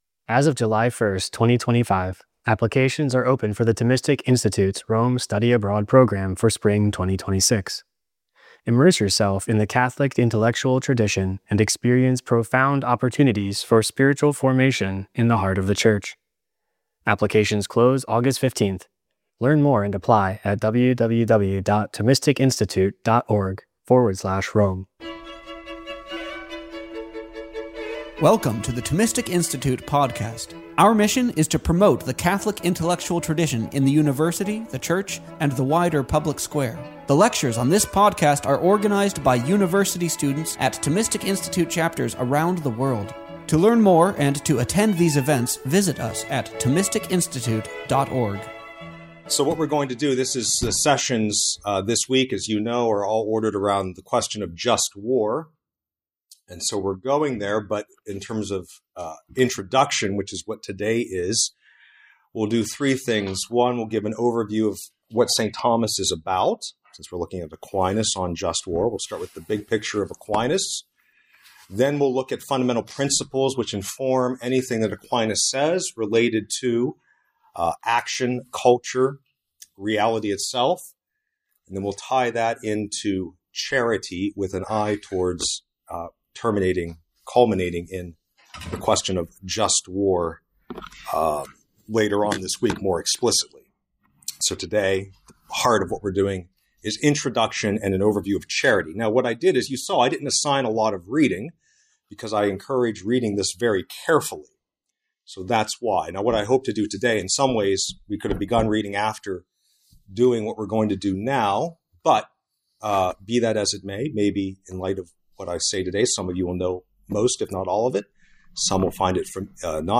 This lecture was given on November 15, 2021 at Oxford University.